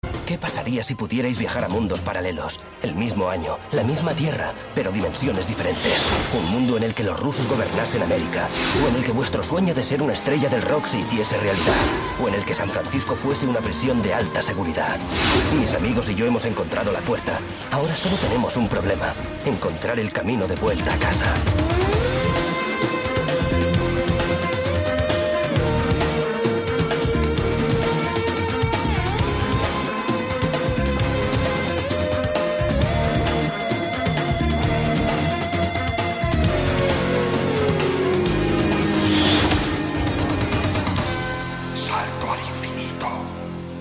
In der 2. Staffel trägt ebenfalls der spanische Synchronsprecher von Quinn Mallory den Vorspanntext vor.
sliders_intro_s2_spanien.mp3